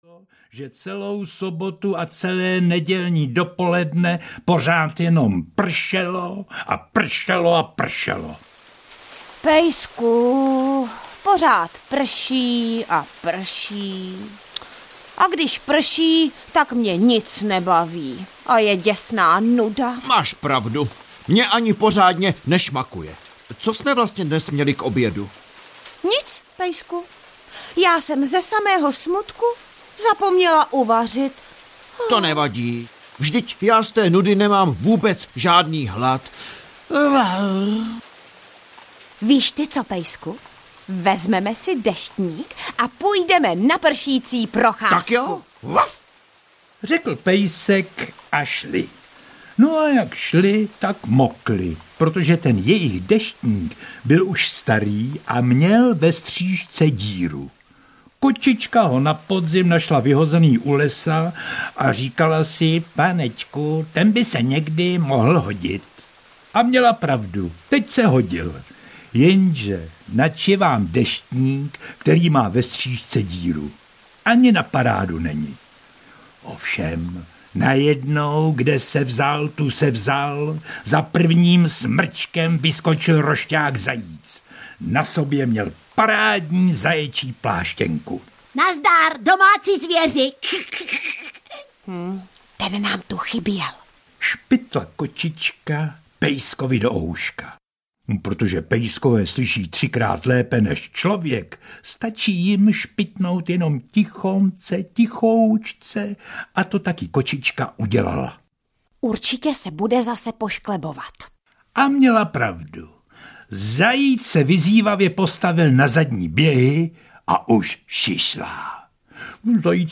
Ukázka z knihy
Jde o dramatizovanou četbu.
V této nahrávce je jeho výkon zralý a vyrovnaný a působí na posluchače velice sympaticky.